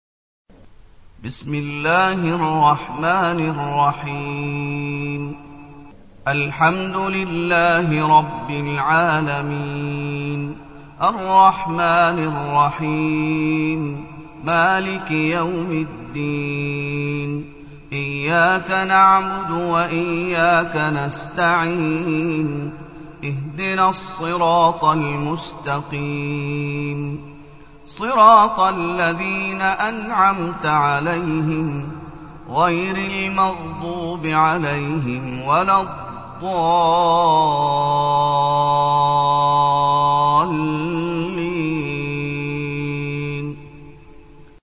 المكان: المسجد النبوي الشيخ: فضيلة الشيخ محمد أيوب فضيلة الشيخ محمد أيوب الفاتحة